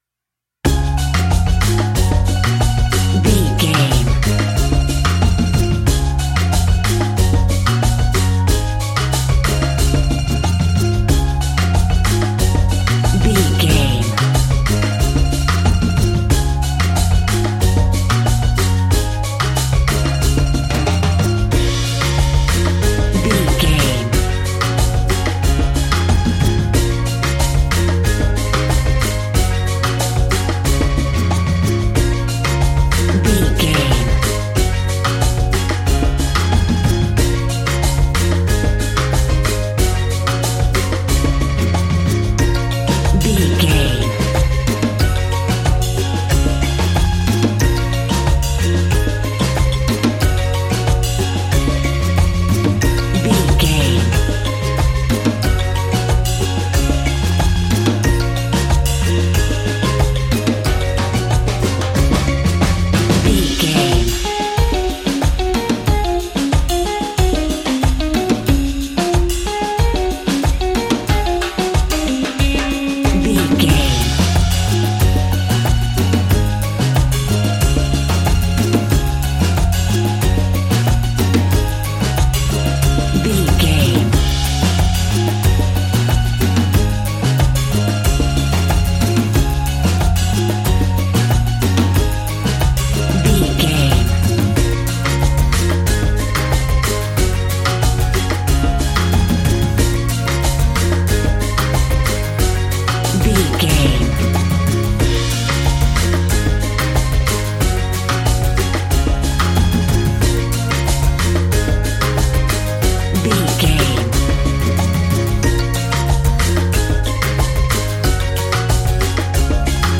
Ionian/Major
D♭
cheerful/happy
mellow
drums
electric guitar
percussion
horns
electric organ